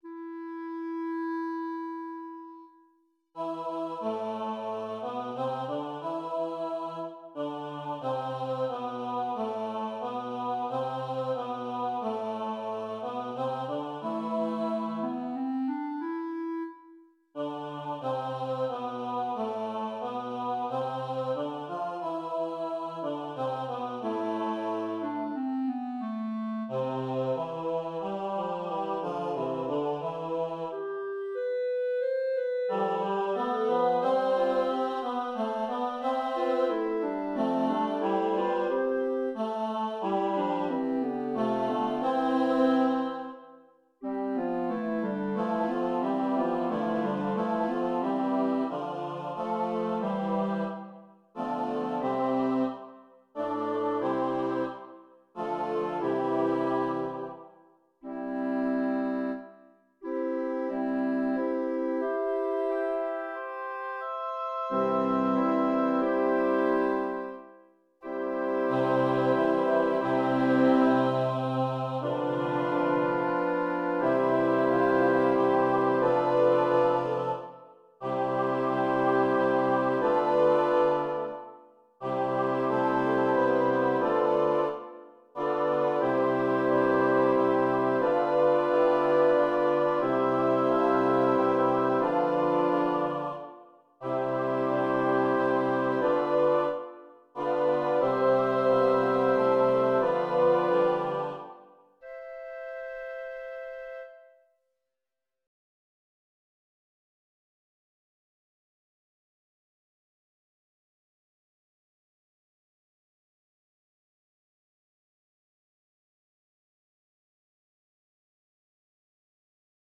Voicing/Instrumentation: SATB , Orchestra